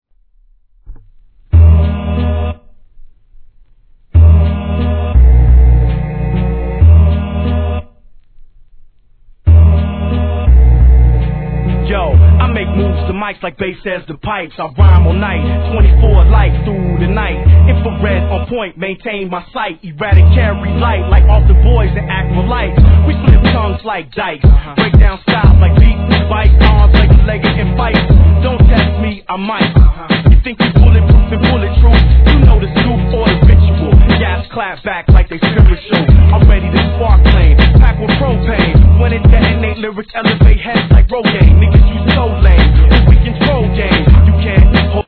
HIP HOP/R&B
タイトなビートに浮遊感ある気持ちのいい上音が重なるトラックに切れ良くリリックを乗せたシカゴスタイルでキメッ！！